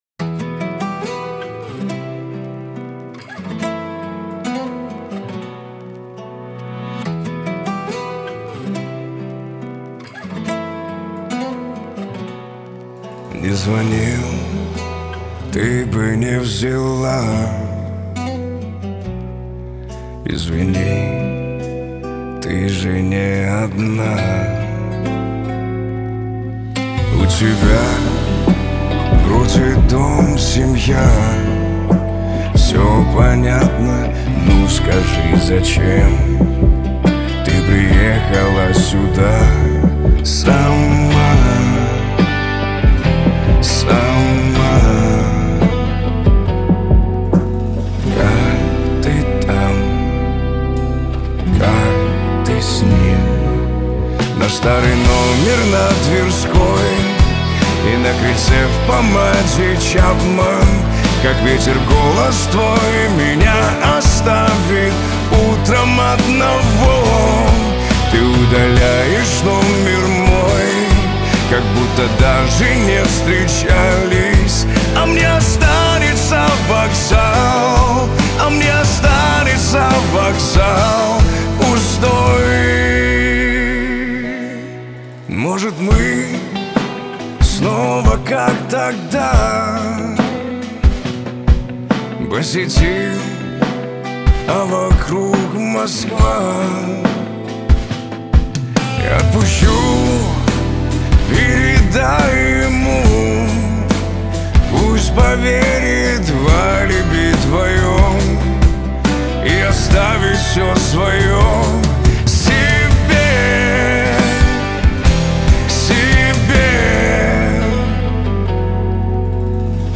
Качество: 320 kbps, stereo
Русский рэп 2025